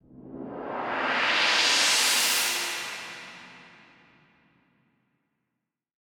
Index of /musicradar/shimmer-and-sparkle-samples/Filtered Noise Hits
SaS_NoiseFilterB-02.wav